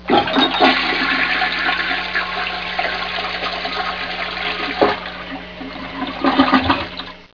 toilet.wav